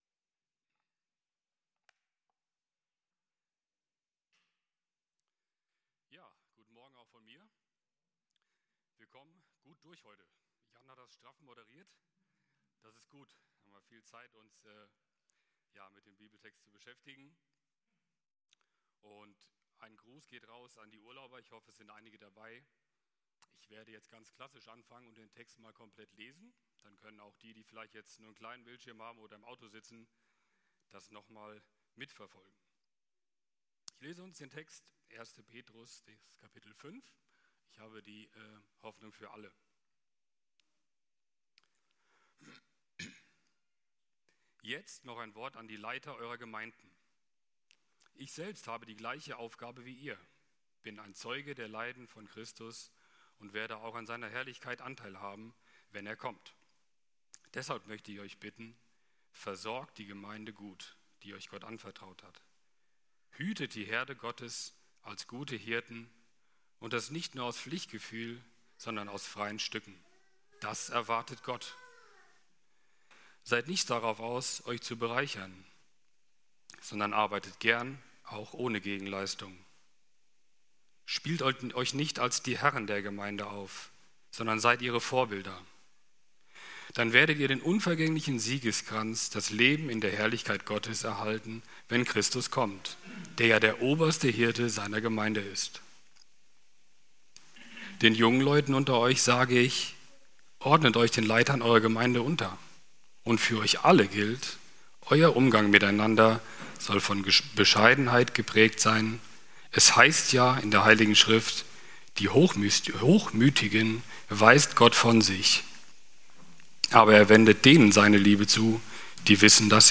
21.07.2024 Wortbetrachtung ~ Predigten - FeG Steinbach Podcast